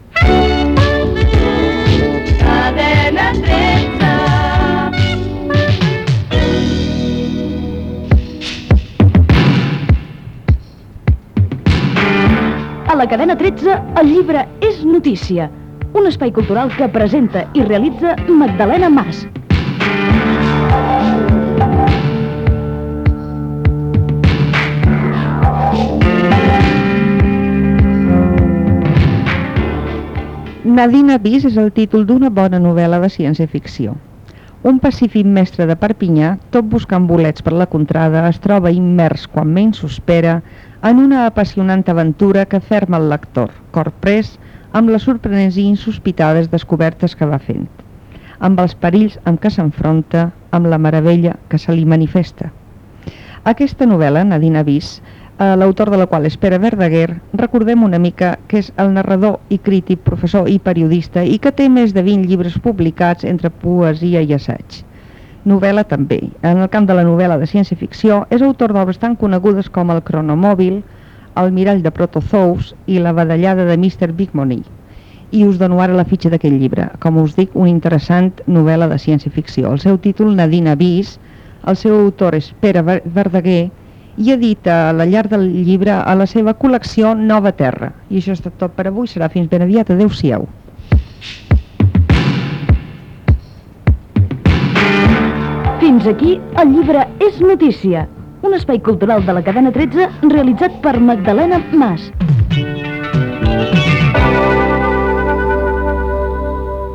Indicatiu cantat Cadena 13. Música i indicatiu parlat de l'espai. Crítica del llibre "Nadina Bis", de Pere Verdaguer. Indicatiu de sortida.
FM